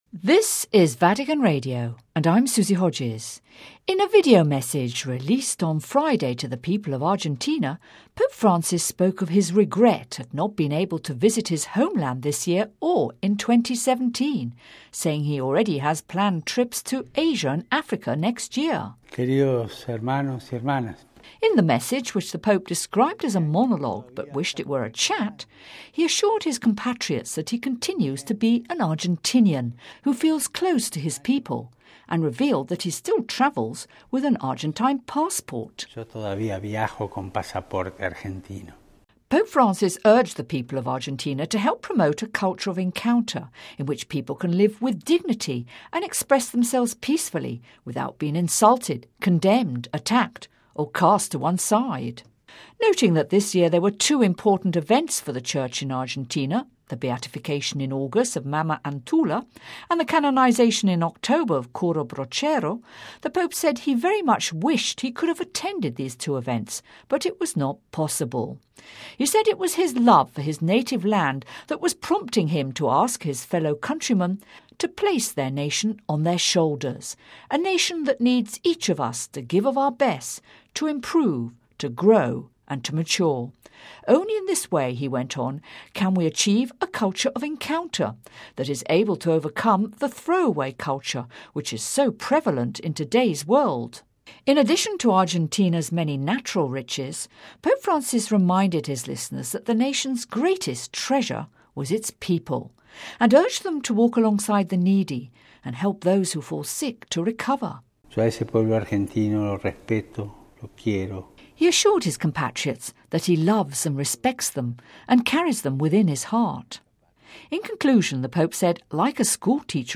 (that includes clips of the Pope's voice reading his message)